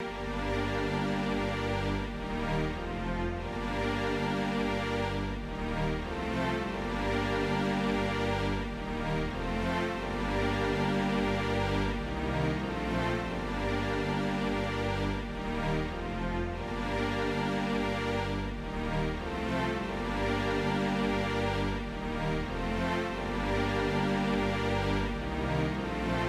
Tag: 146 bpm Trap Loops Strings Loops 4.43 MB wav Key : Unknown Studio One